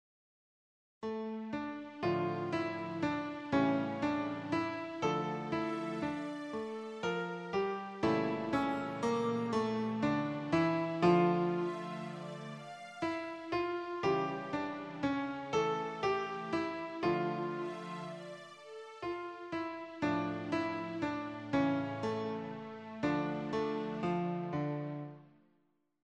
Рубрика: Поезія, Авторська пісня
Она чуть иначе записана.